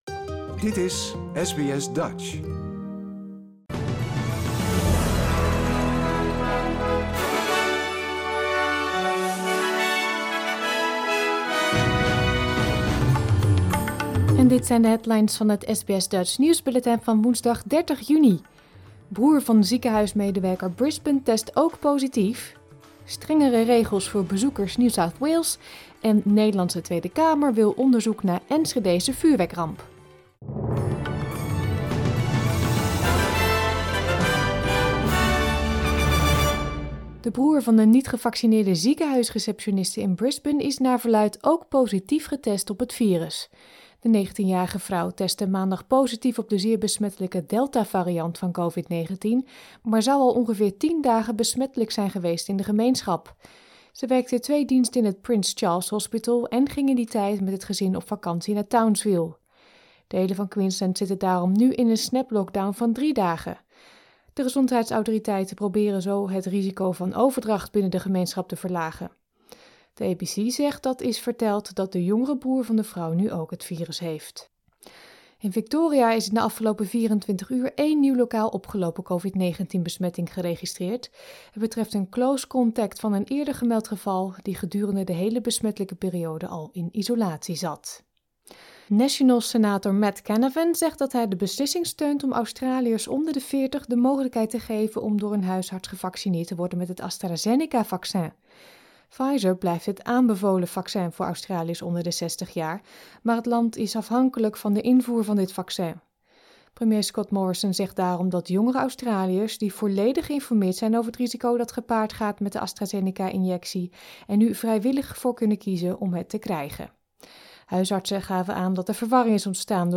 Nederlands/Australisch SBS Dutch nieuwsbulletin van woensdag 30 juni 2021